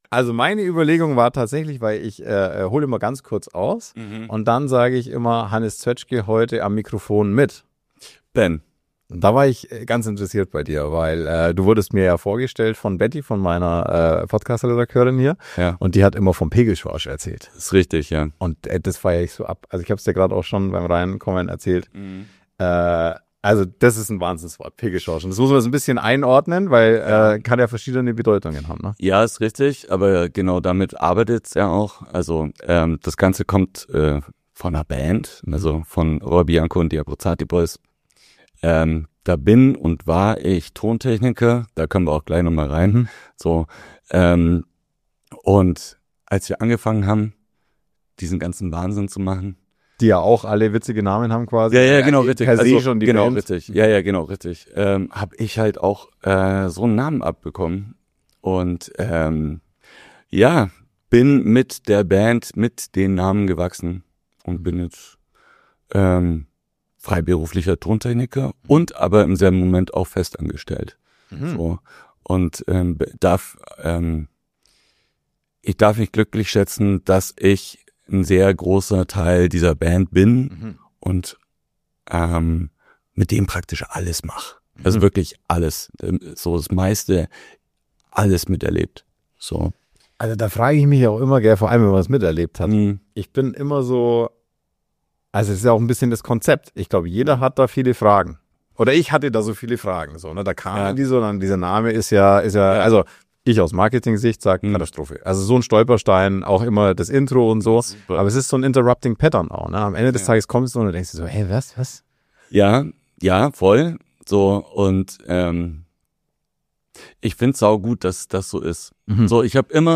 Beschreibung vor 1 Woche Heute habe ich einen Mann zu Gast, dessen Namen man vielleicht nicht sofort auf jedem Plakat sieht, dessen Arbeit man aber auf den größten Bühnen Deutschlands hört.